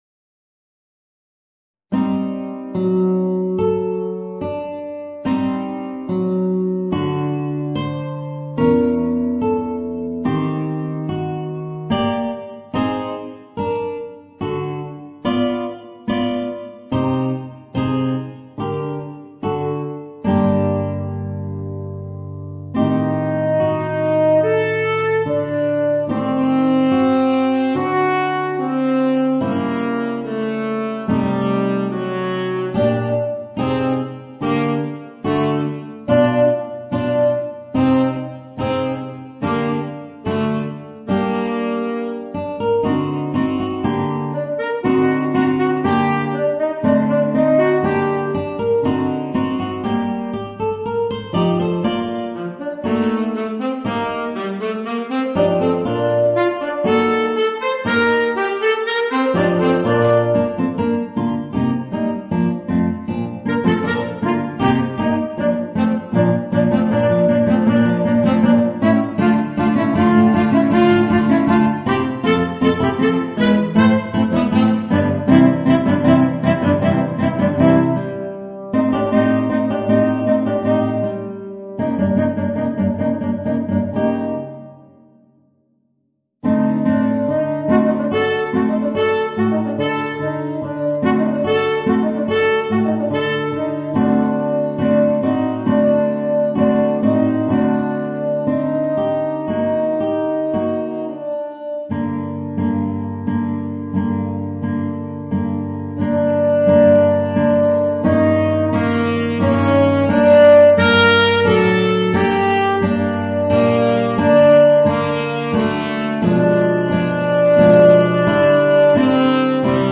Voicing: Tenor Saxophone and Guitar